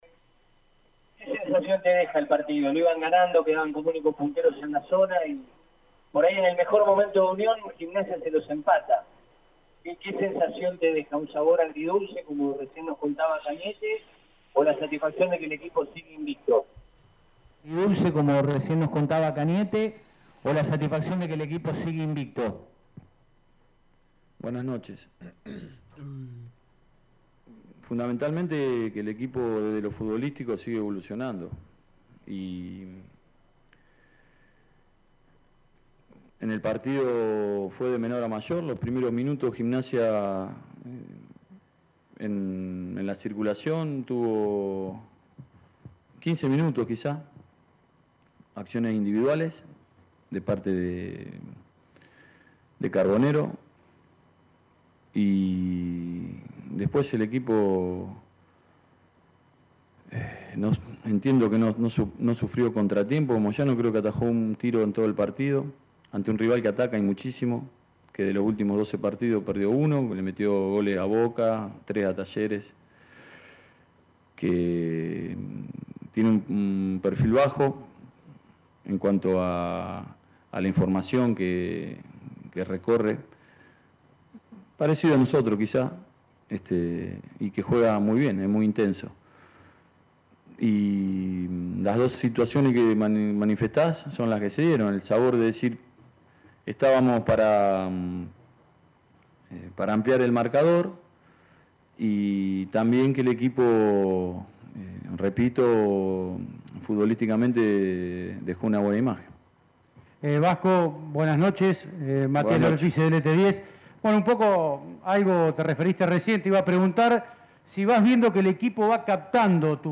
habló en conferencia de prensa